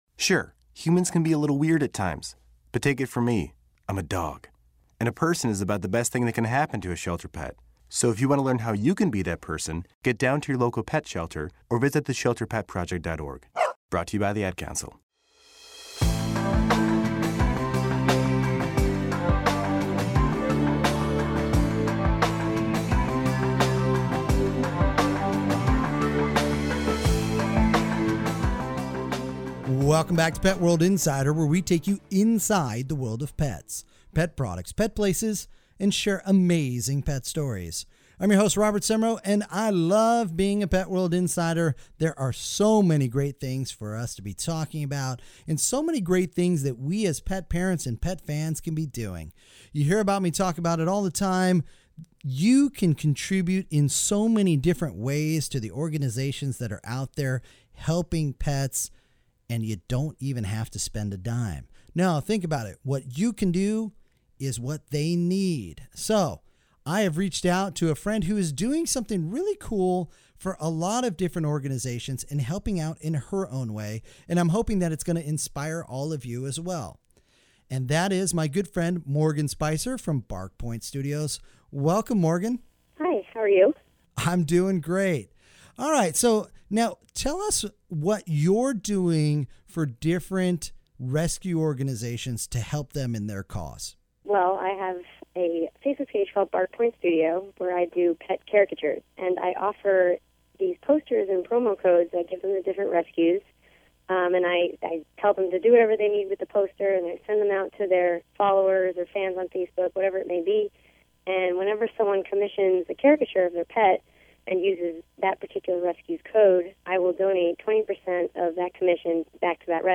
On this Pet World Radio Segment talk with our good friend and very talented artist
Enjoy this Pet World Radio Segment in case a station near you does not currently carry Pet World Radio on the EMB or CRN networks!